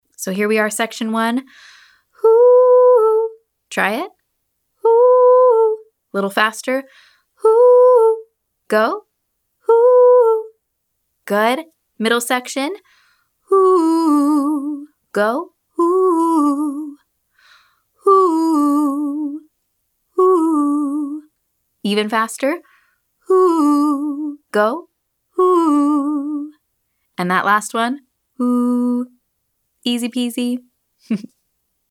Improving Speed - Online Singing Lesson